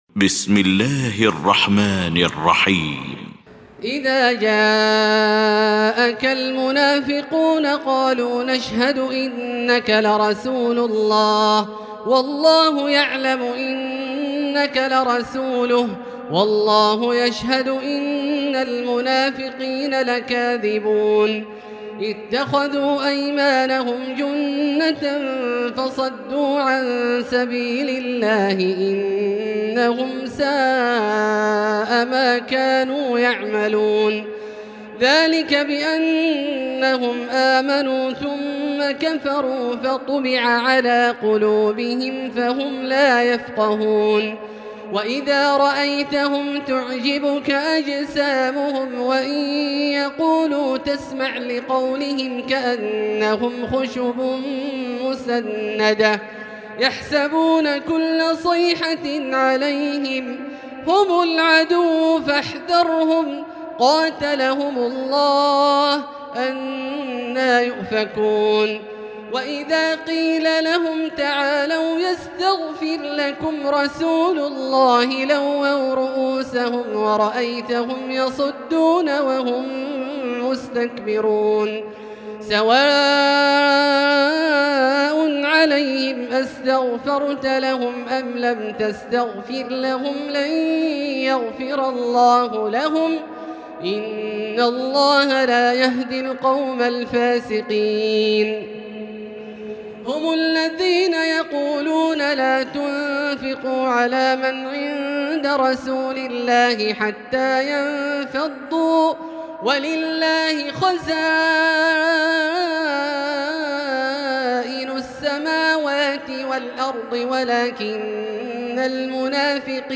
المكان: المسجد الحرام الشيخ: فضيلة الشيخ عبدالله الجهني فضيلة الشيخ عبدالله الجهني المنافقون